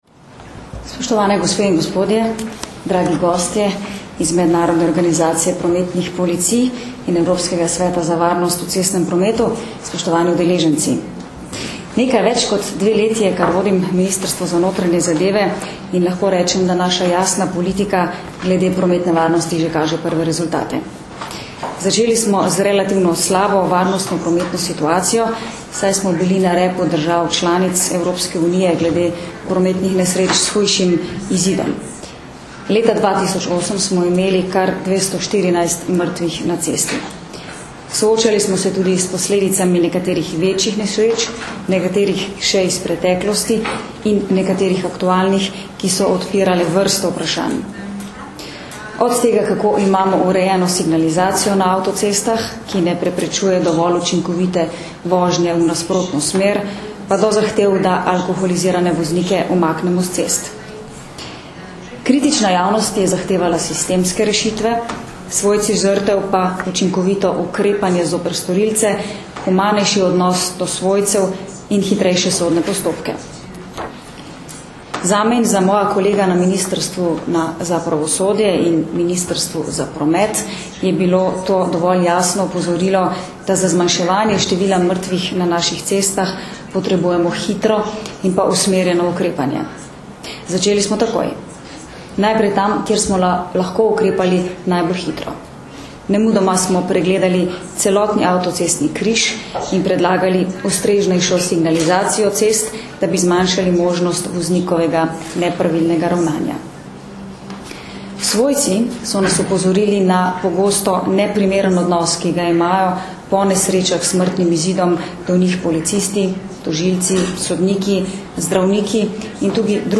Na Bledu se je začel mednarodni strateški seminar o prometni varnosti
Zvočni posnetek nagovora ministrice za notranje zadeve Katarine Kresal (mp3)